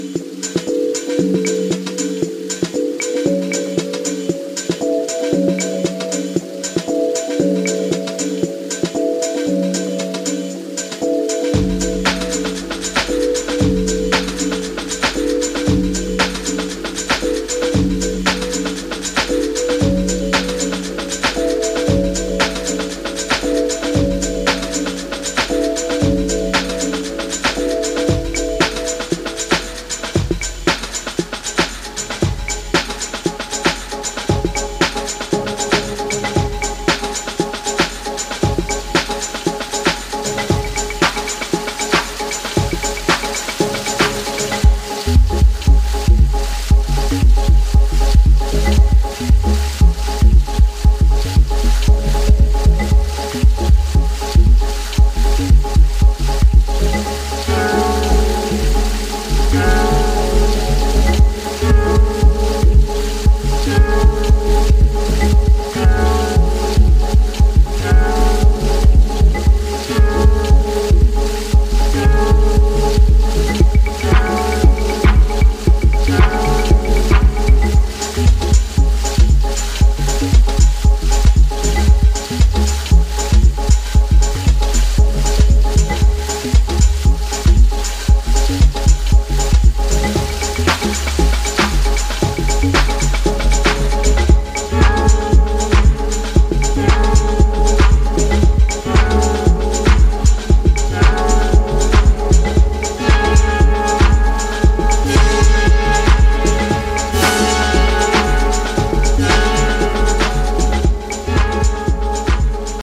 House Ambient